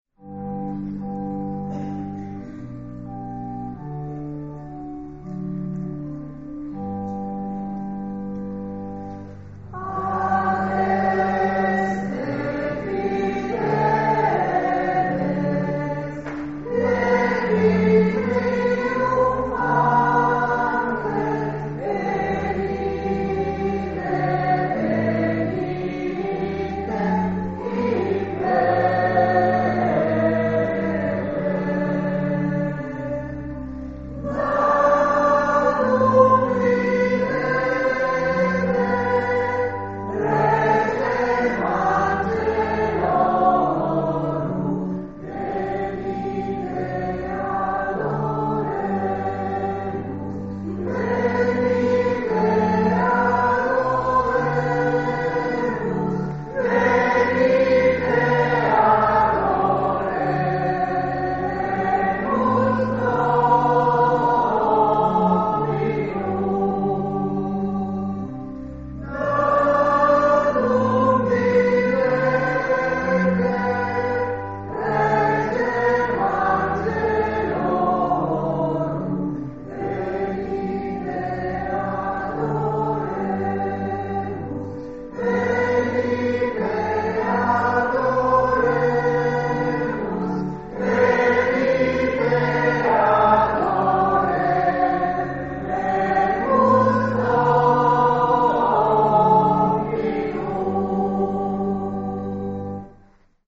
Istituto Gervasutta di Udine (UD), 22 Dicembre 2024
Santa Messa dell'Ammalato
Accompagnata dalla "Corale Gioconda"
CANTO E PREGHIERE DI APERTURA
La Corale Gioconda è un coro costituito da persone affette dalla malattia di Parkinson e da alcuni dei loro familiari.